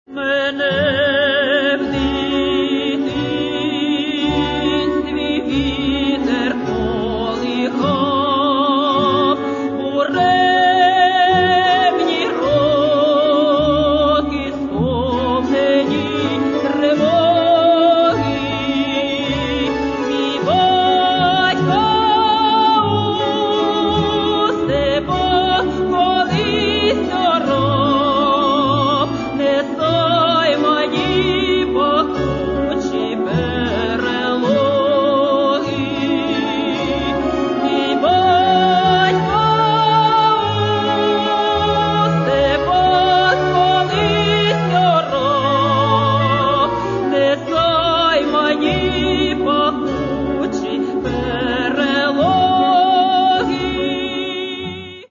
Каталог -> Эстрада -> Певицы